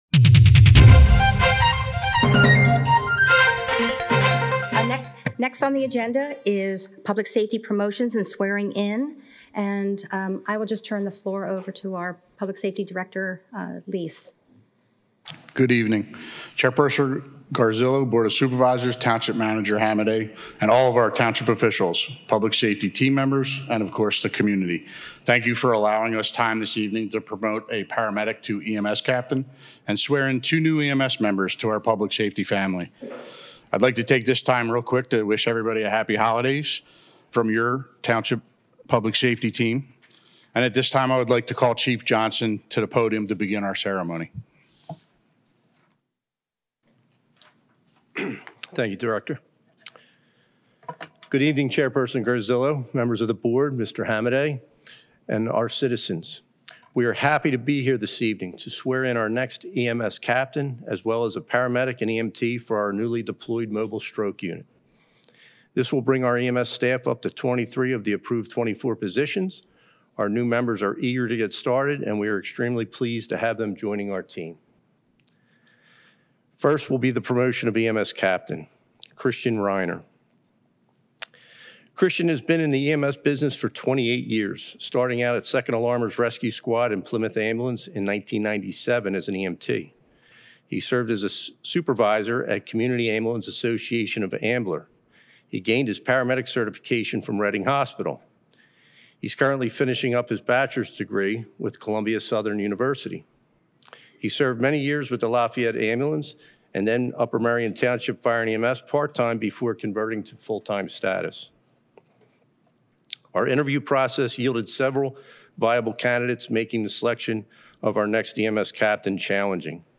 Public Safety Promotions and Swearing-In